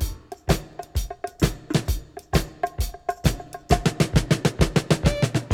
• 131 Bpm Modern Drum Beat F# Key.wav
Free drum groove - kick tuned to the F# note. Loudest frequency: 1276Hz
131-bpm-modern-drum-beat-f-sharp-key-w1w.wav